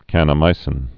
(kănə-mīsĭn)